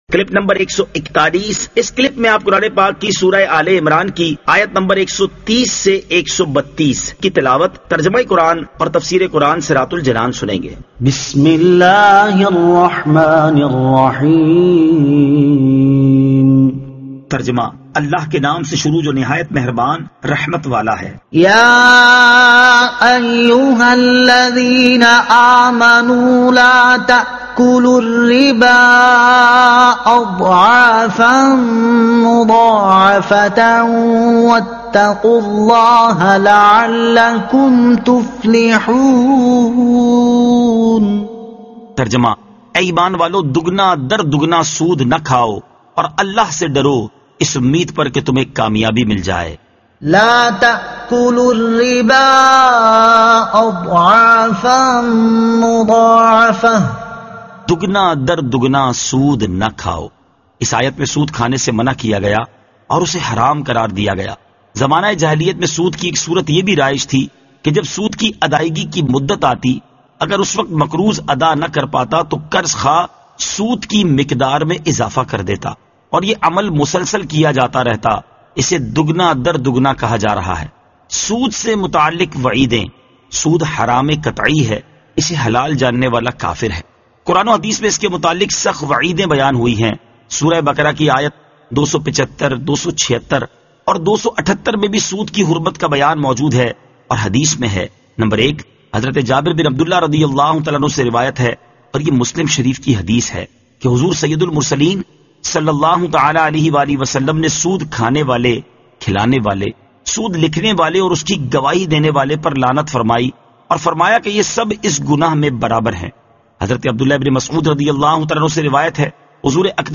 Surah Aal-e-Imran Ayat 130 To 132 Tilawat , Tarjuma , Tafseer